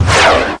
1 channel
feuerwerk_zisch2.mp3